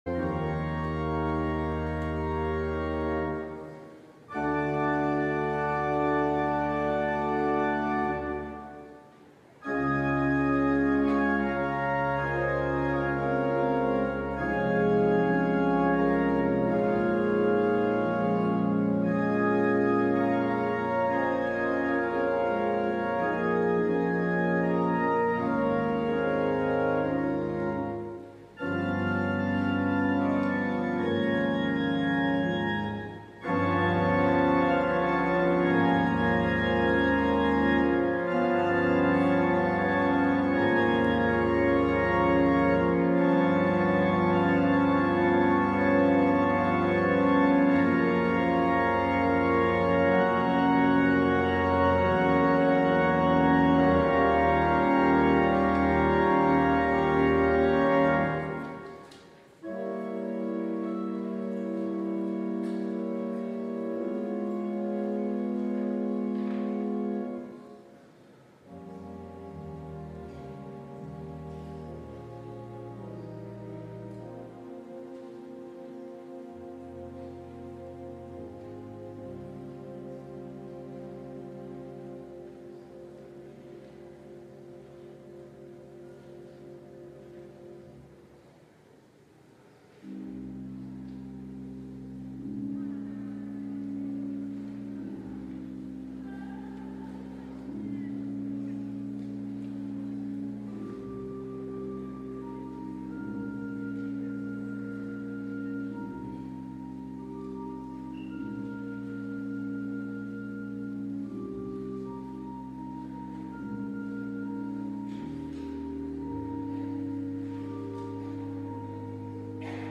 LIVE Morning Worship Service - Even Though
Congregational singing—of both traditional hymns and newer ones—is typically supported by our pipe organ.